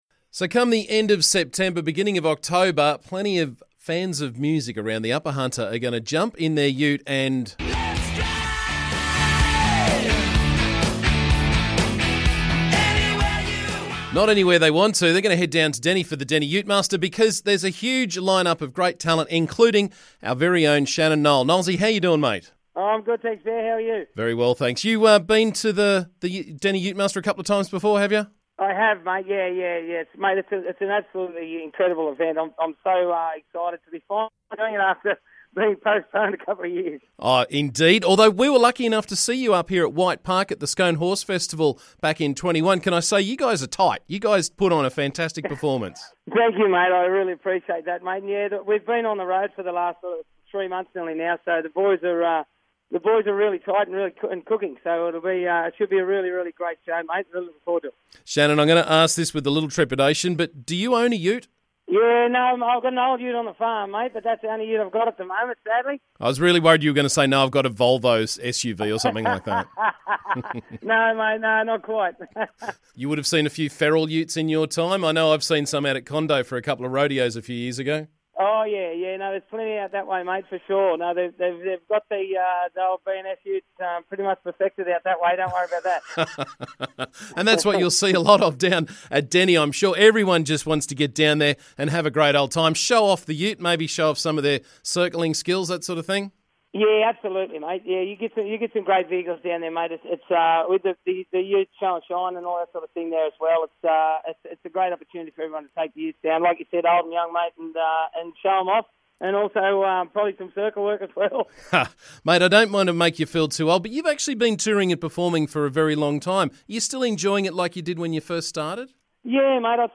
Shannon Noll and his band are on their way to perform at the annual Deni Ute Muster and he had a few minutes to chat about touring and utes on the show today.